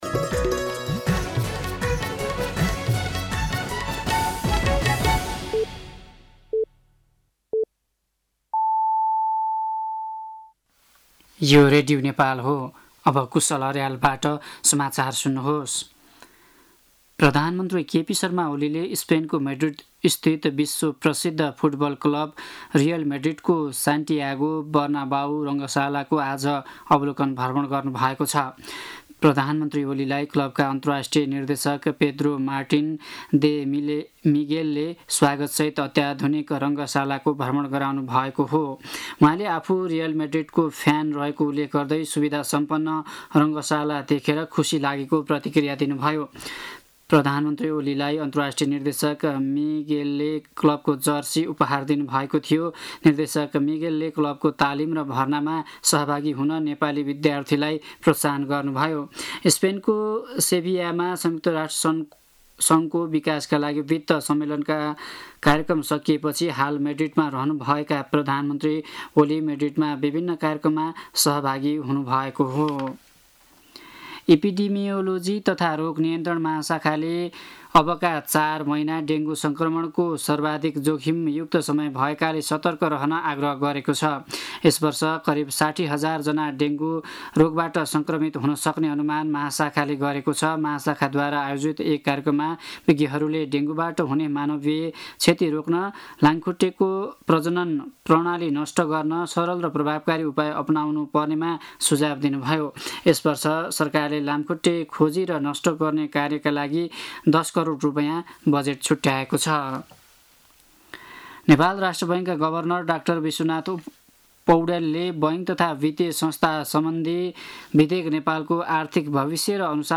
साँझ ५ बजेको नेपाली समाचार : १९ असार , २०८२
5.-pm-nepali-news-.mp3